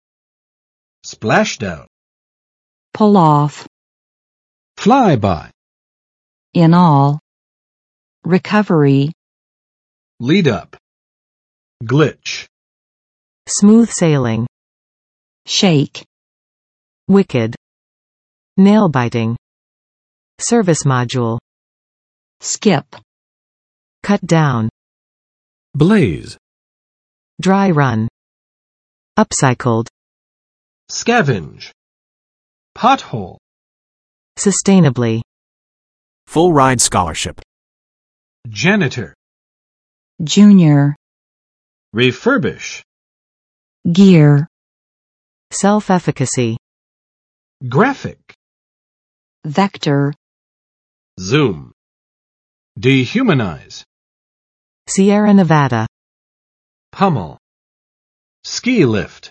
[ˋsplæʃ͵daʊn] n.（飞弹或宇宙飞船的）在海中降落
splashdown.mp3